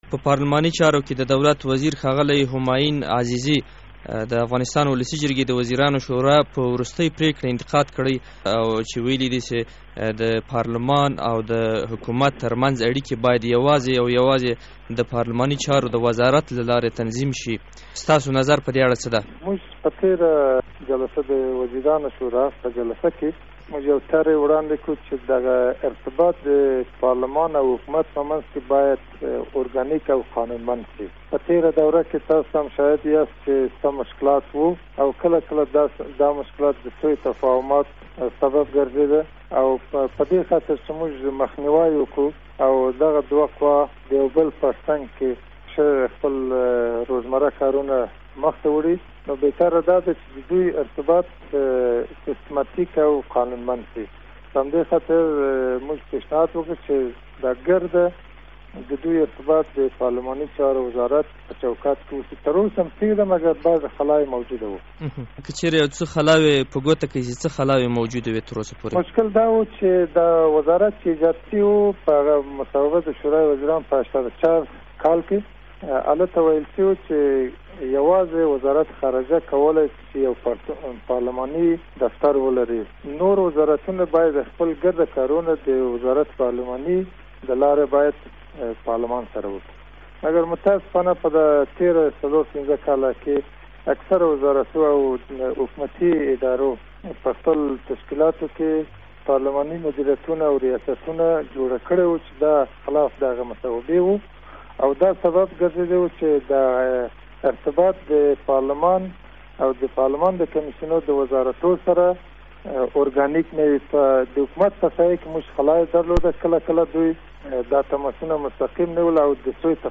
ځانګړې مرکه